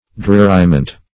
Dreariment \Drear"i*ment\ (dr[=e]r"[i^]*ment)